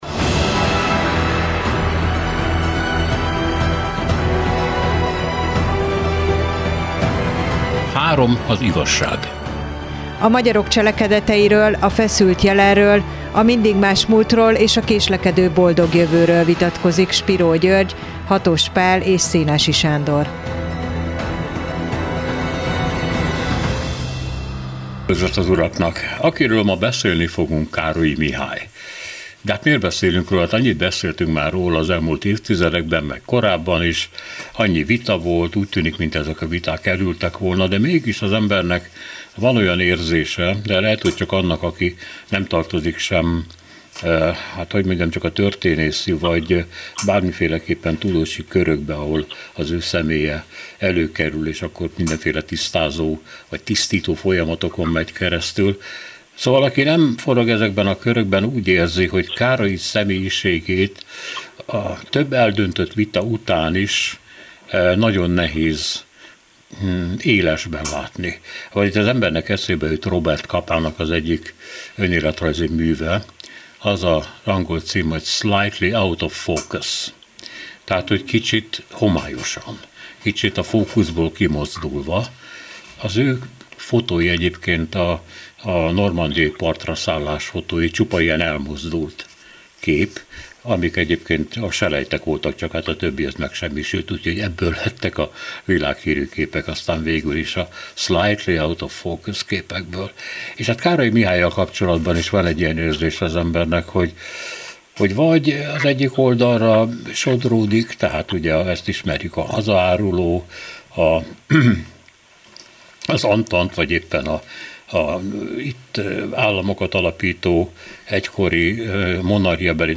1 Grecsó Krisztián: Nemcsak transzgenerációs adósságokat kapunk, hanem hiteleket is” 1:06:19 Play Pause 3h ago 1:06:19 Play Pause Lejátszás később Lejátszás később Listák Tetszik Kedvelt 1:06:19 Pár hete jelent meg Grecsó Krisztián új regénye, az Apám üzent, ami egyfelől a múlt század hazai történelmét átfogó, több generációra visszatekintő családregény, közben meg a szerző saját identitásának alakulását bemutató, rendkívül személyes mű. A Nem rossz könyvek új részében Grecsó Krisztiánnal beszélgettünk olyan kérdésekről, mint hogy egyáltal…